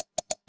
mouse1.wav